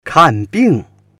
kan4bing4.mp3